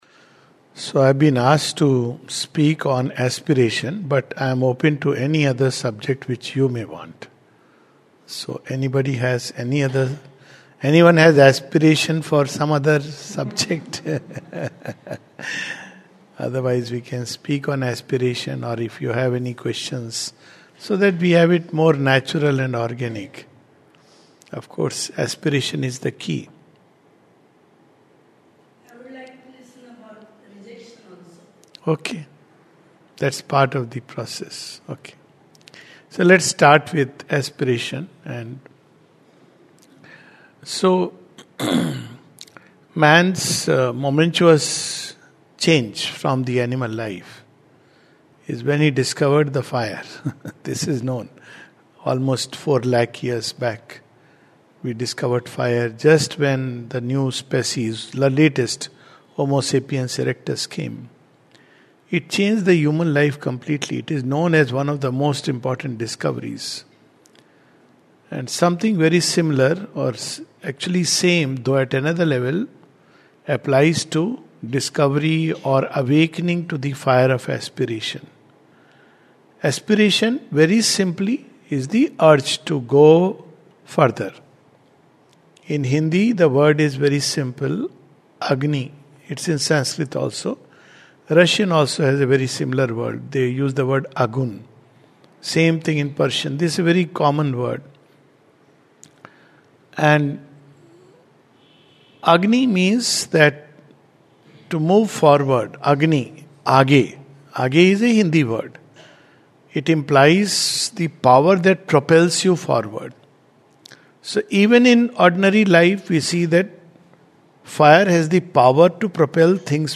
This talk is part of the Sri Aurobindo Society program. There are questions and answers connected to the talk.